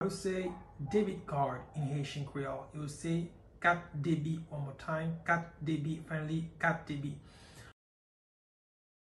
“Kat debi” Pronunciation in Haitian Creole by a native Haitian can be heard in the audio here or in the video below:
Debit-card-in-Haitian-Creole-Kat-debi-pronunciation-by-a-Haitian-teacher.mp3